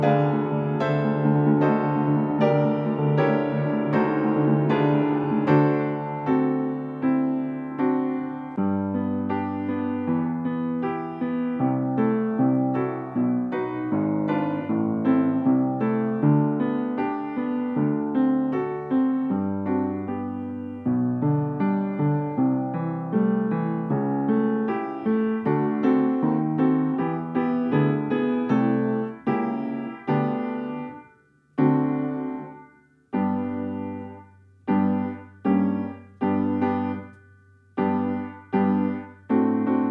I wersja: 78 BMP
Nagrania dokonane na pianinie Yamaha P2, strój 440Hz
piano